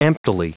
Prononciation du mot emptily en anglais (fichier audio)
Prononciation du mot : emptily
emptily.wav